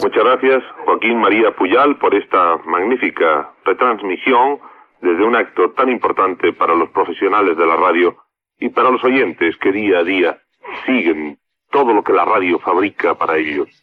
Retorn de la connexió als estudis de la Cadena SER a Madrrid.
Informatiu